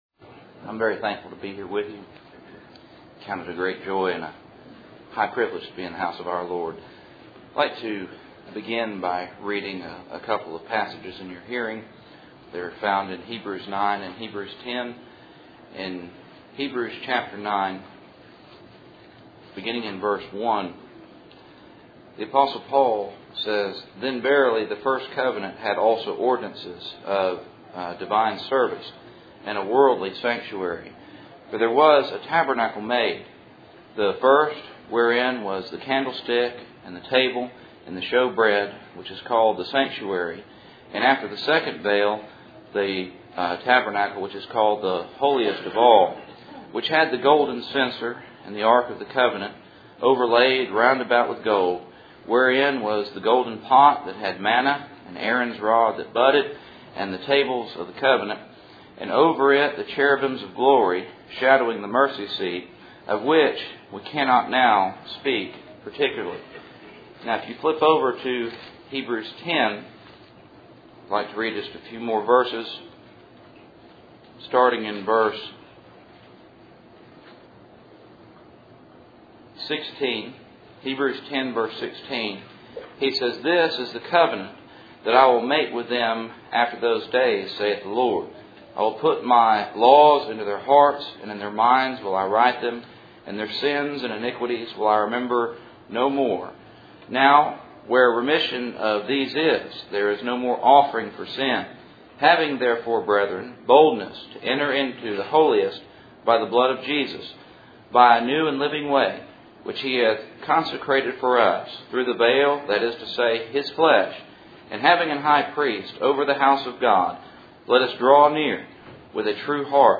Passage: Hebrews 9:10 Service Type: Cool Springs PBC Sunday Morning %todo_render% « Joseph